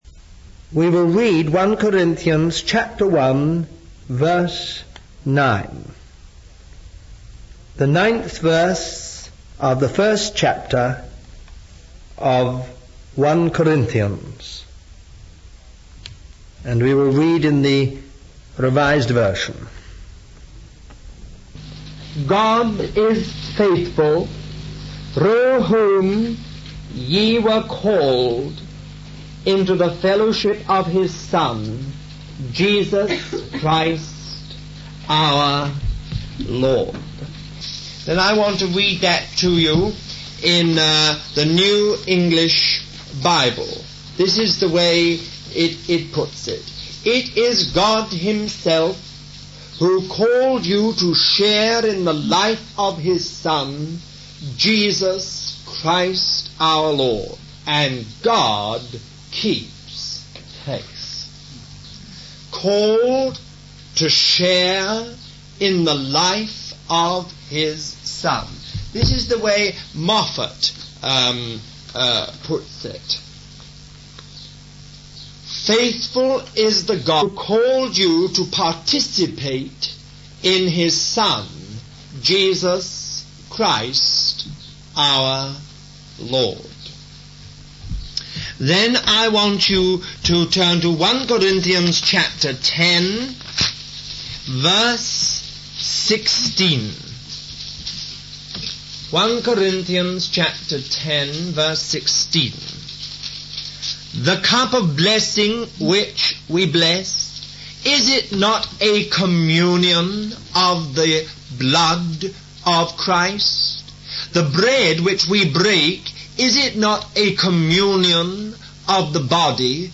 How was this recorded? Halford House, England